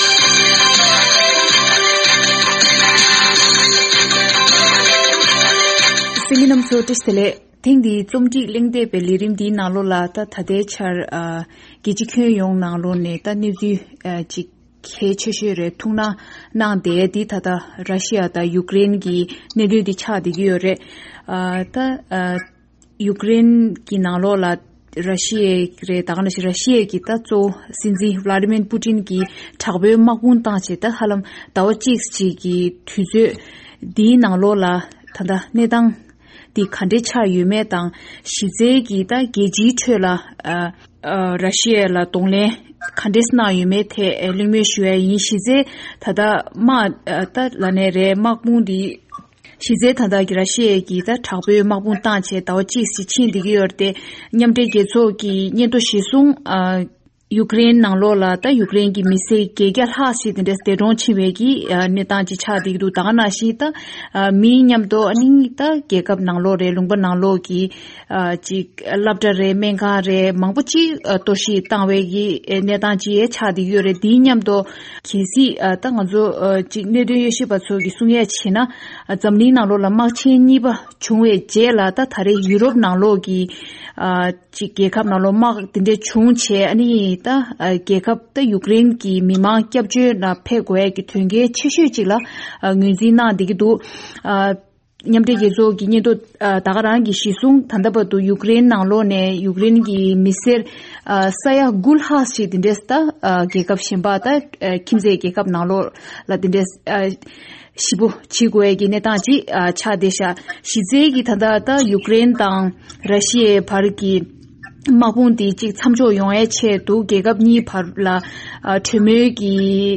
དཔྱད་གཞིའི་གླེང་མོལ་ཞུས་པའི་ལས་རིམ།